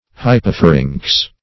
Search Result for " hypopharynx" : The Collaborative International Dictionary of English v.0.48: Hypopharynx \Hy`po*phar"ynx\, n. [NL.
hypopharynx.mp3